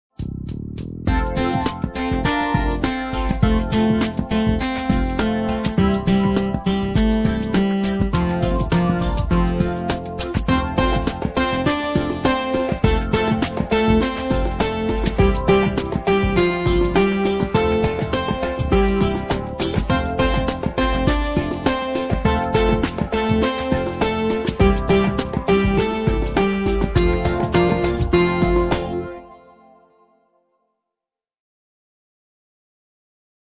Oefening 5 (sneller dan op de oefen-cd)
Oefening5(sneller).mp3